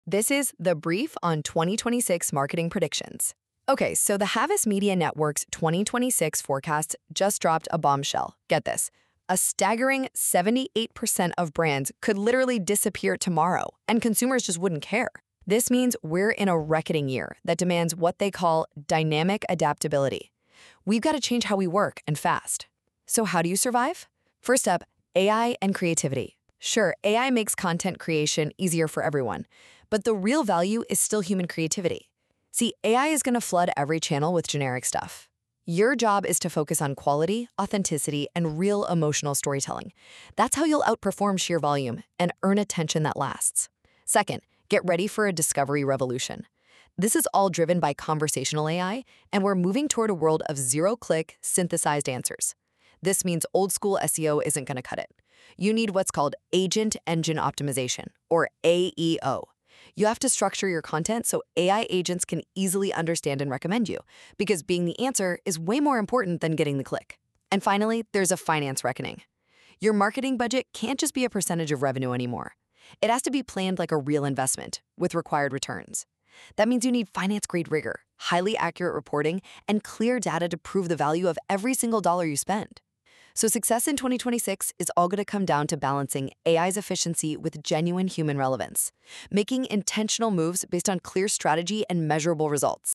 I uploaded HMN's just-released "2026 Predictions Report" into NotebookLM to map the mind of its authors and break it down into a simple audio overview.